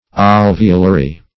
alveolary - definition of alveolary - synonyms, pronunciation, spelling from Free Dictionary Search Result for " alveolary" : The Collaborative International Dictionary of English v.0.48: Alveolary \Al"ve*o*la*ry\, a. Alveolar.